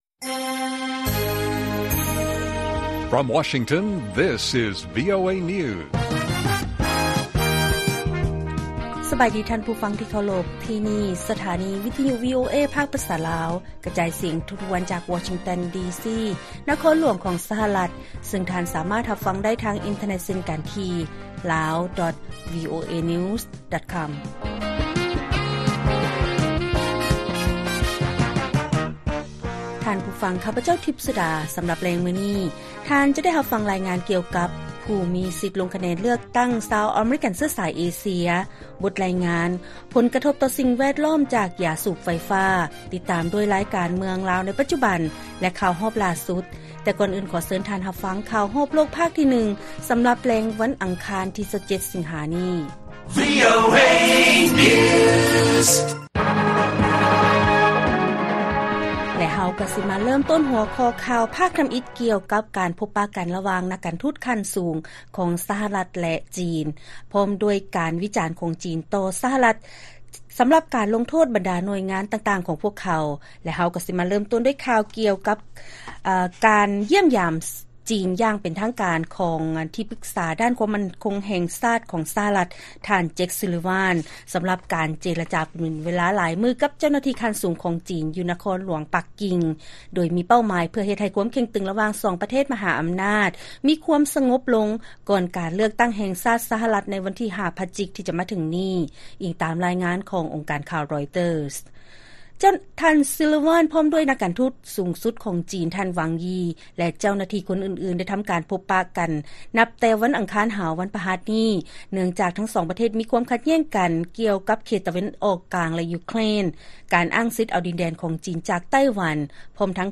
ລາຍການກະຈາຍສຽງຂອງວີໂອເອ ລາວ: ຜູ້ມີສິດເລືອກຕັ້ງຊາວອາເມຣິກັນເຊື້ອຊາຍເອເຊຍ ມີຄວາມຫຼາກຫຼາຍທາງດ້ານວັດທະນະທຳ ແລະ ທັດສະນະທາງດ້ານການເມືອງ.